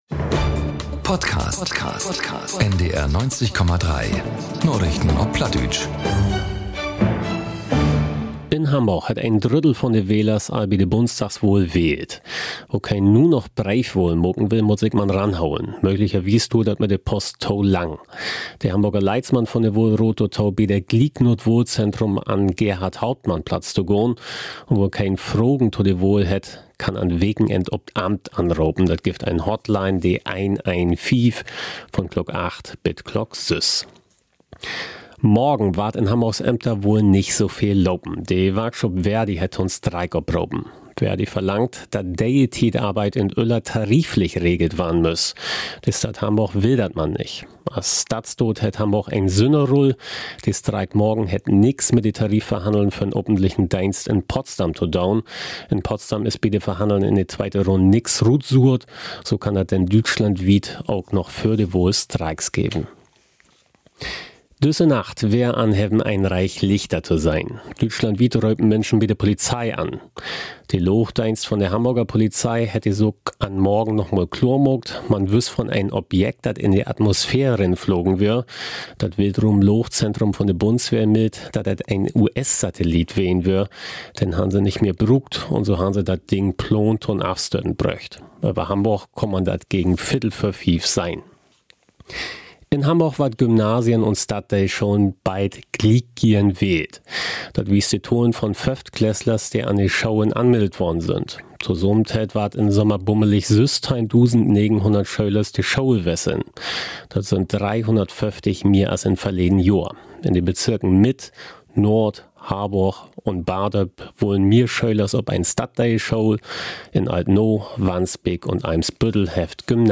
1 Narichten op Platt 13.12.2024 3:26 Play Pause 4d ago 3:26 Play Pause در پخش در آینده در پخش در آینده لیست ها پسندیدن دوست داشته شد 3:26 Ut Hamborg und de wiede Welt vun maandaags bet sünnavends: Die aktuellen Nachrichten auf Plattdeutsch.